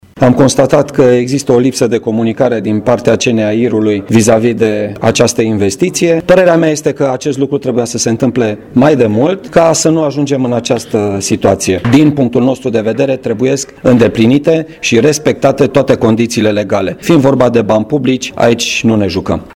Aici se desfășura o conferință de presă, legată de tergiversarea dării în folosință a pasarelei peste centura ocolitoare a municipiului Brașov, din dreptul localitatății Sânpetru.
Prefectul Brașovului, Marian Rasaliu s-a arătat total nemulțumit de această situație și a cerut Companiei Naționale de Administrare a Infrastructurii Rutiere să avanseze un termen clar de dare în folosință a obiectivului: